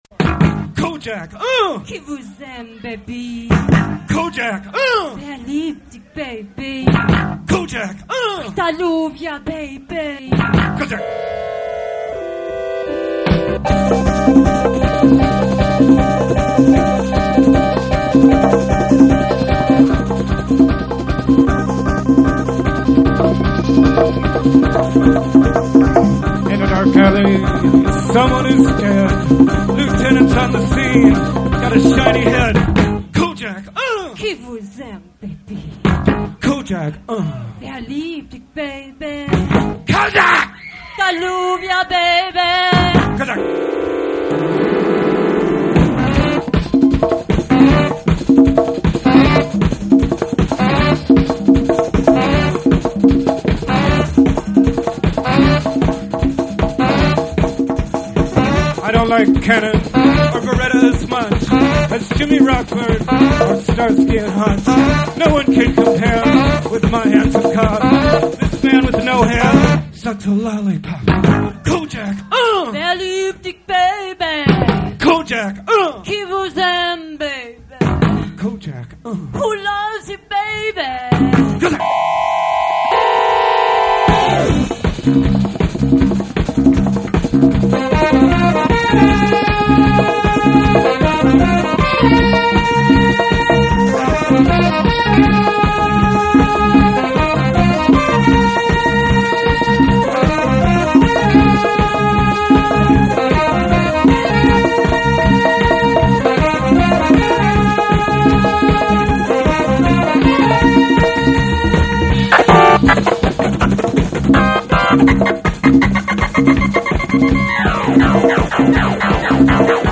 Live at Trax NYC 1981